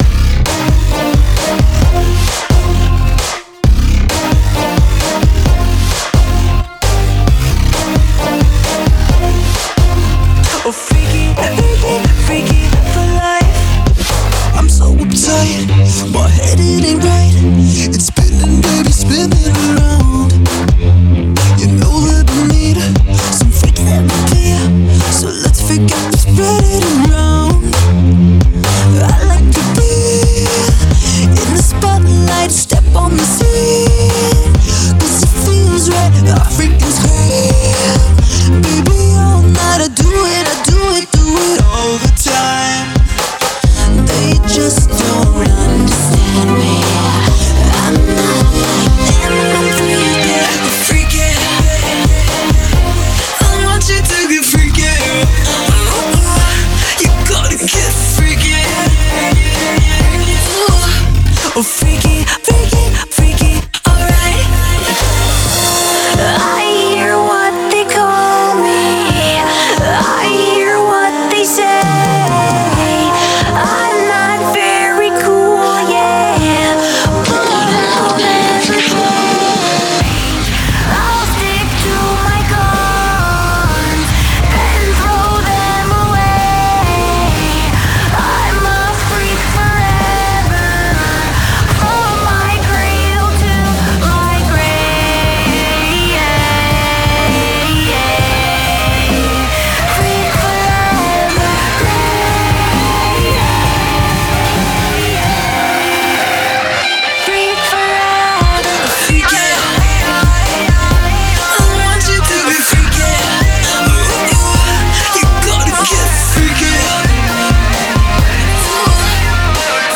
BPM132
MP3 QualityMusic Cut